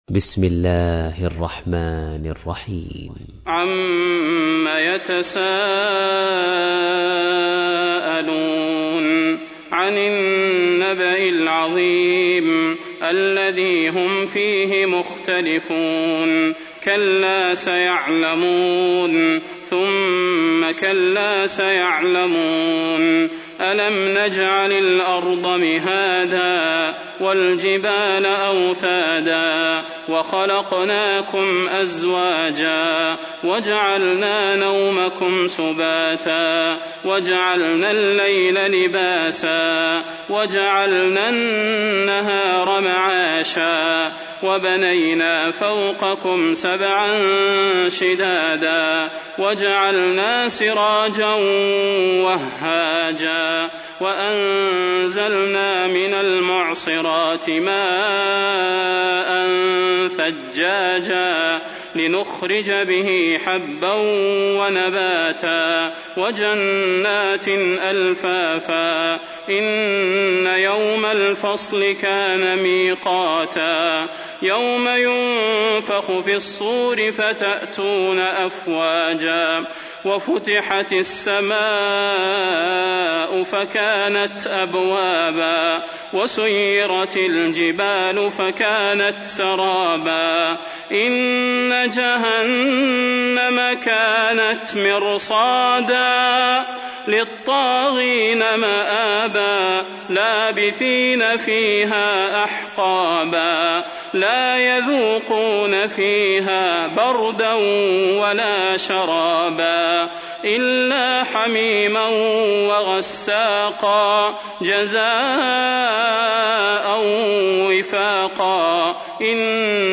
تحميل : 78. سورة النبأ / القارئ صلاح البدير / القرآن الكريم / موقع يا حسين